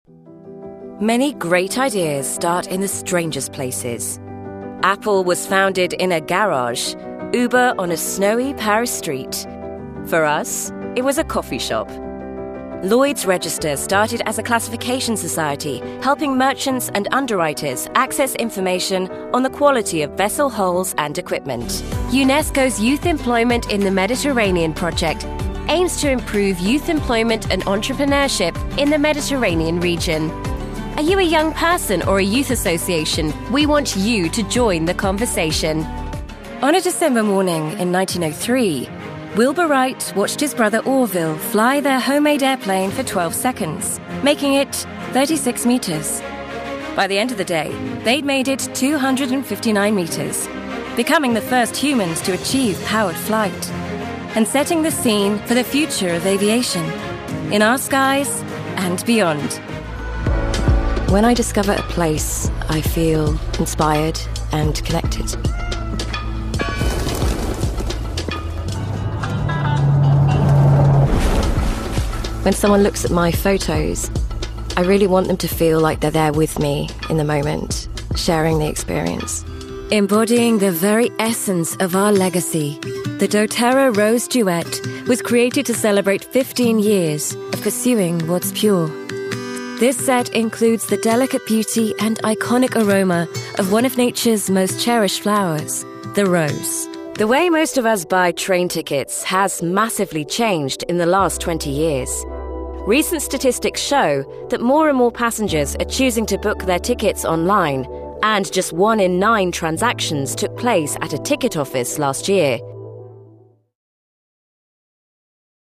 Vídeos corporativos
Micrófonos: Neumann U87, Neumann TLM 102, Sennheiser MKH 416
Cabina: Cabina vocal de doble pared a medida de Session Booth con paneles añadidos de EQ Acoustics, Auralex y Clearsonic.